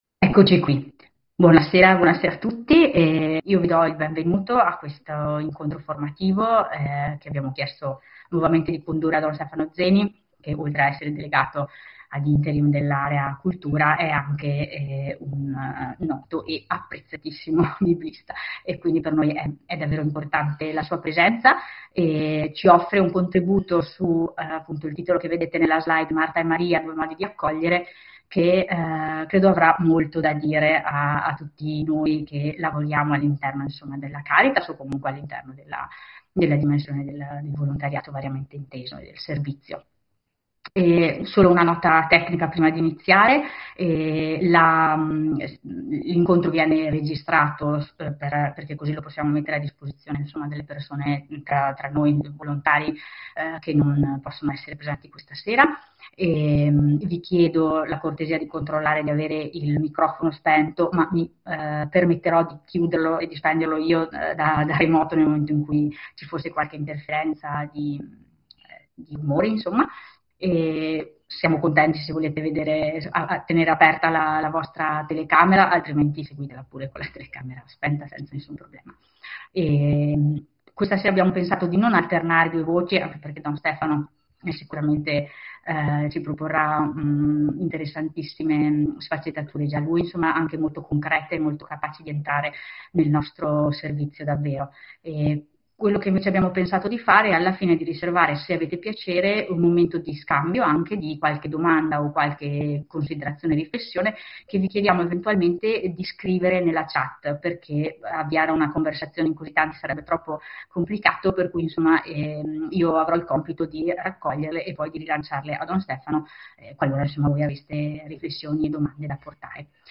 Condividiamo qui l’audio completo dell’incontro e le slide utilizzate del relatore.